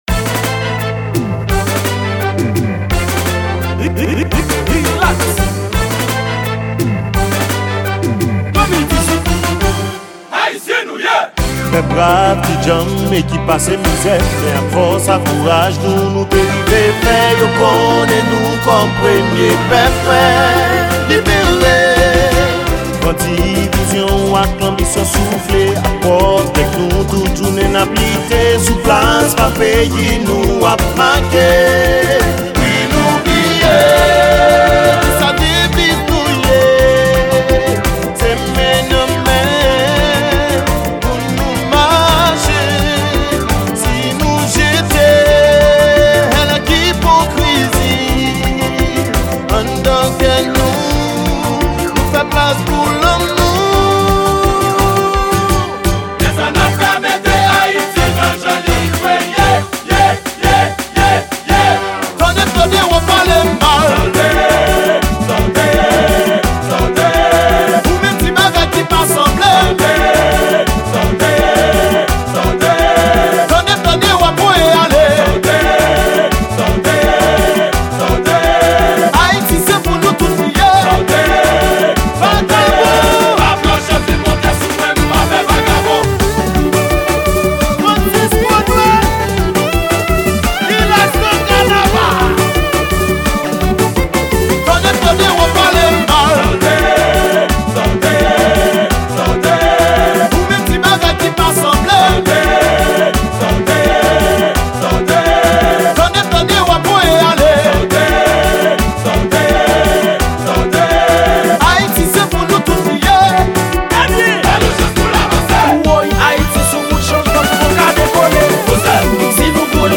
Genre : KANAVAL